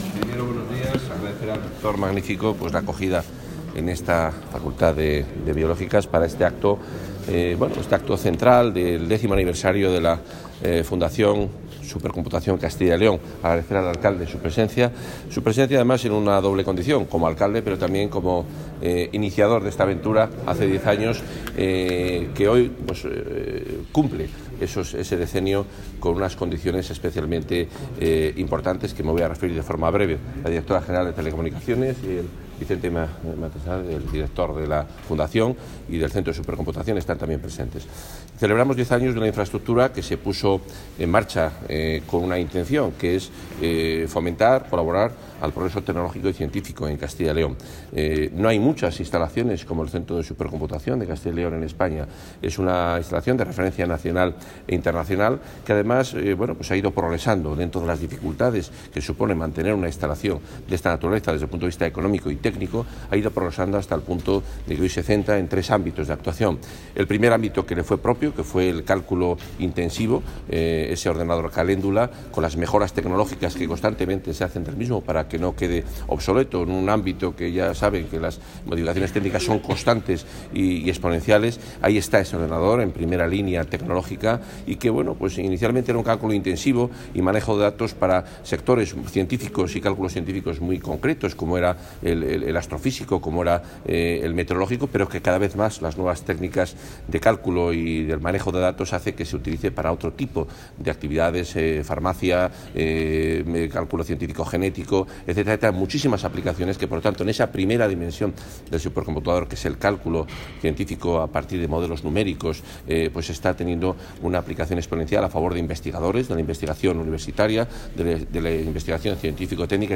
Declaraciones del consejero de Fomento y Medio Ambiente.